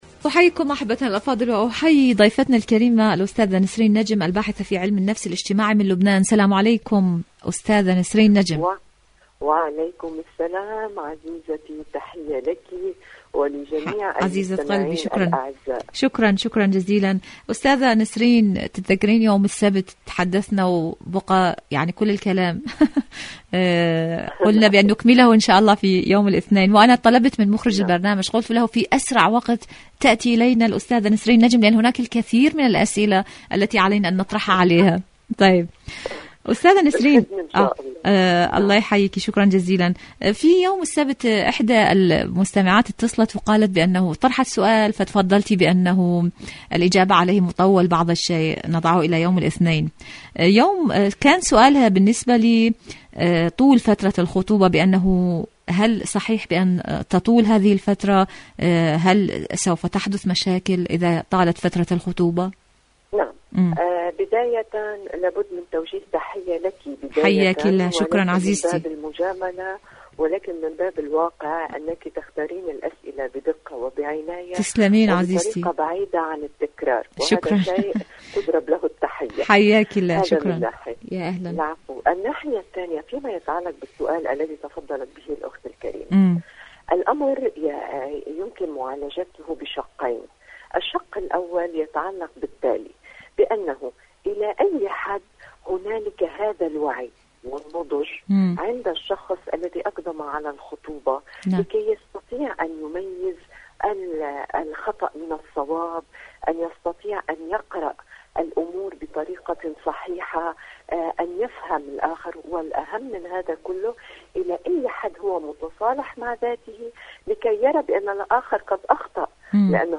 مقابلات برامج إذاعة طهران مقابلات إذاعية برنامج دنيا الشباب الشباب الخطوبة فترة الخطوبة الشباب وفترة الخطوبة شاركوا هذا الخبر مع أصدقائكم ذات صلة آليات إيران للتعامل مع الوكالة الدولية للطاقة الذرية..